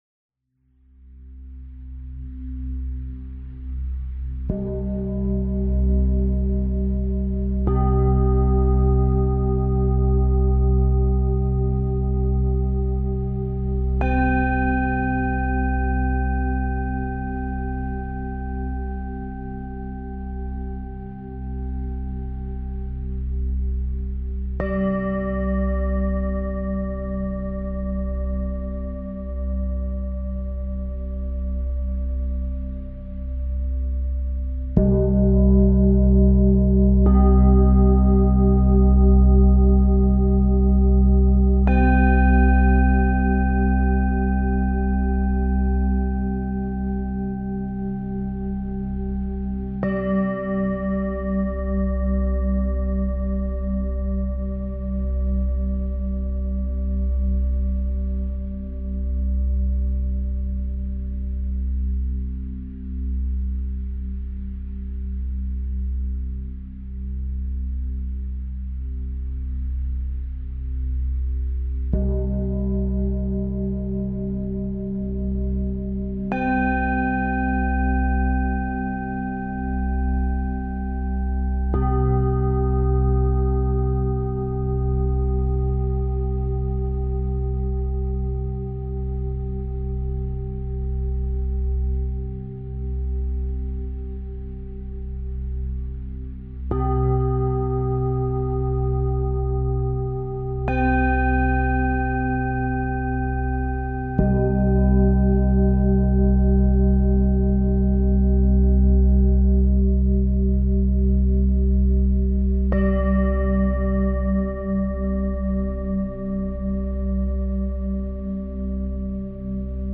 I call it my sound meditation.
Find a quiet spot, close your eyes, and focus on deep slow breathing as you follow the gong.
3-Minute-per-Chakra-Tune-UP.mp3